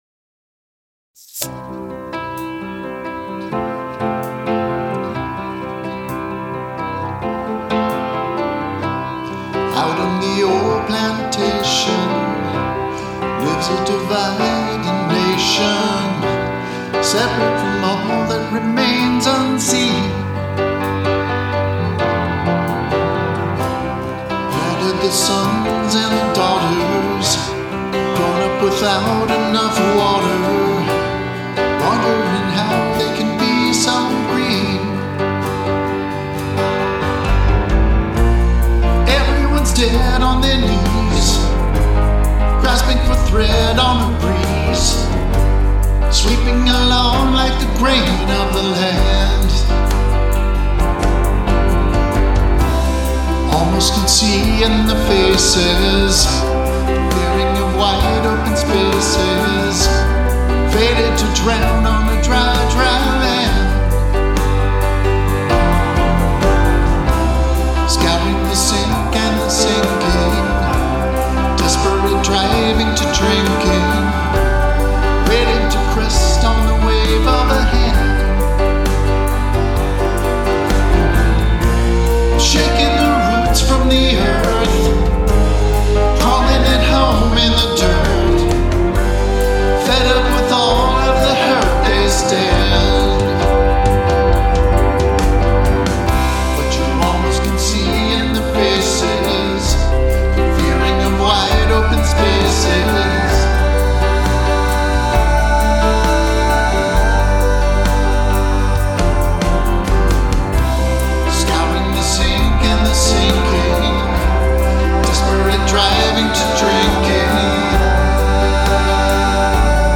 1st major effort at a complete Garageband track; open for opinions and help
Finally I entered the digital age--but my favorite piano track for it was still the original 1987 one, which I edited/overdubbed.